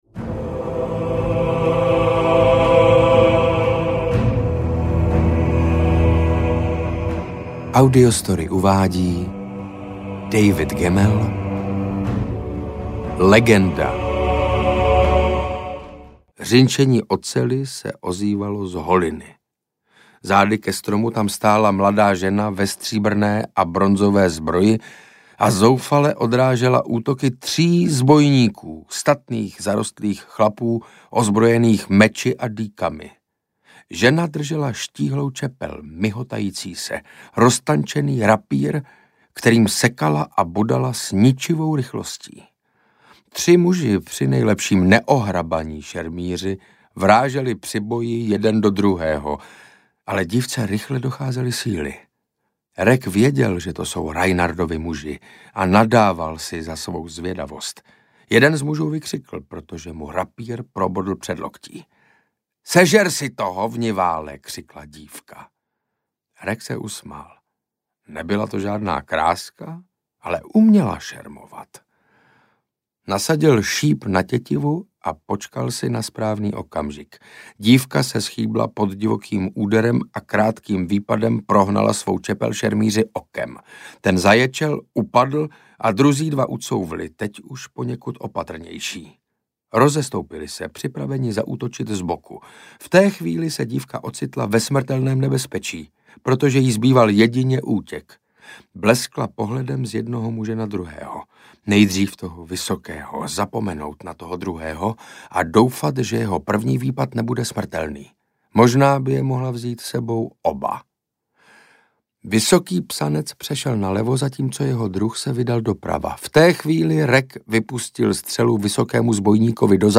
Legenda audiokniha
Ukázka z knihy
• InterpretSaša Rašilov